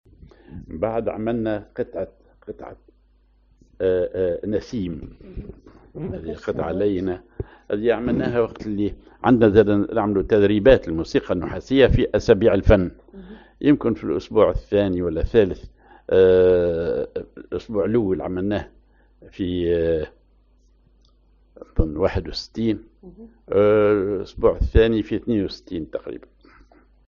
بوسلك نوا (صول صغير)